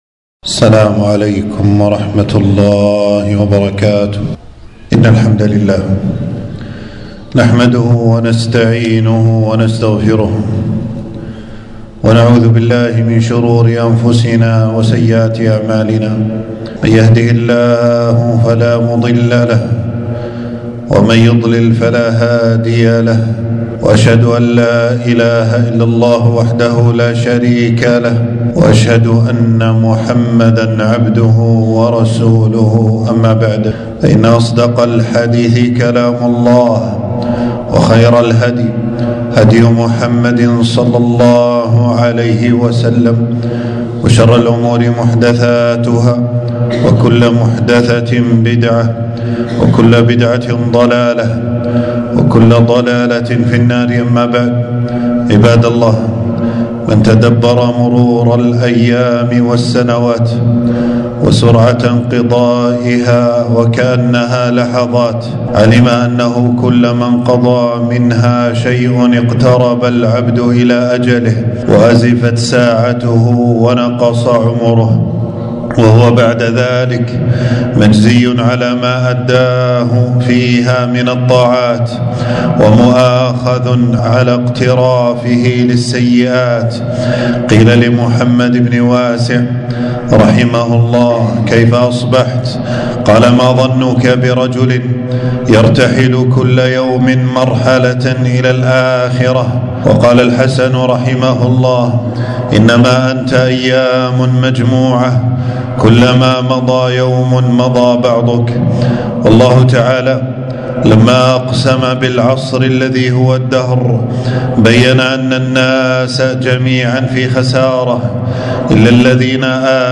خطبة - استغلال الإجازات في فعل الطاعات